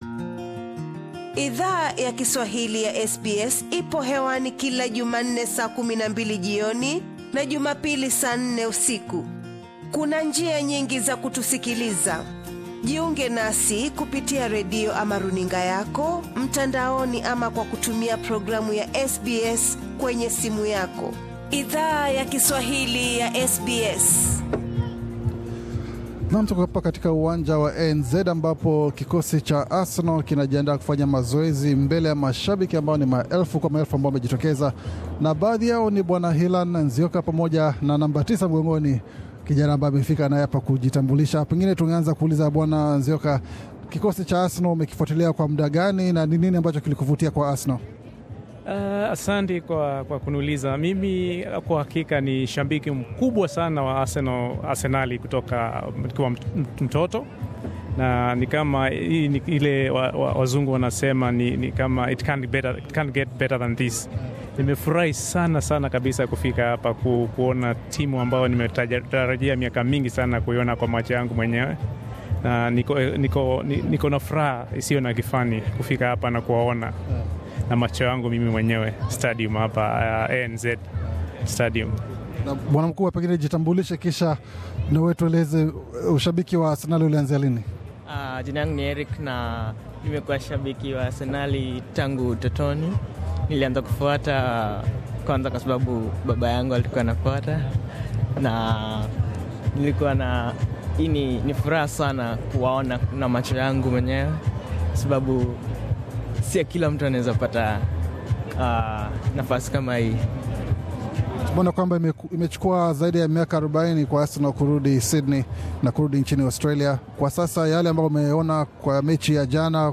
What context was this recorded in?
Mashabiki wa Arsenal FC walijitokeza kwa ma mia yama elfu katika uwanja wa michezo wa ANZ, kutazama mechi za vigogo hao wa ligi kuu ya Uingereza mjini Sydney. SBS Swahili ilihudhuria mazoezi hayo, ambapo mashabiki wali alikwa naku zungumza na baadhi yao.